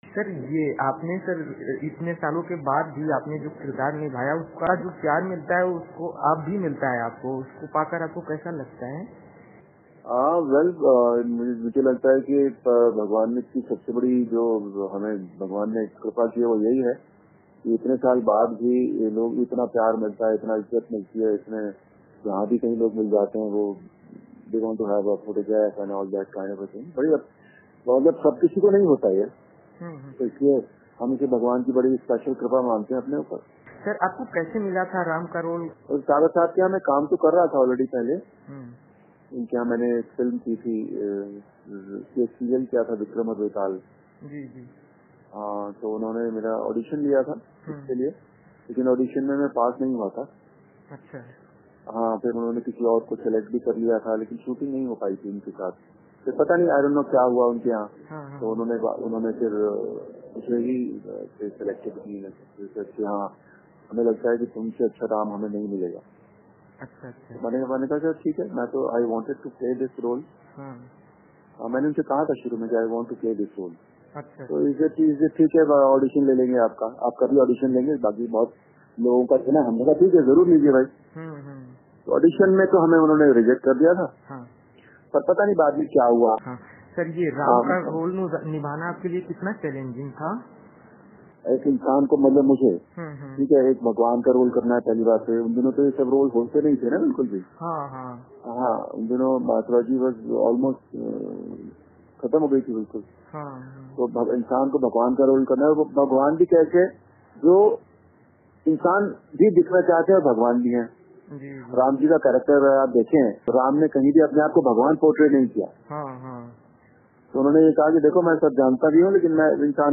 इस मौके पर क्‍व‍िंट ने इस राम से विस्‍तार से बातचीत की.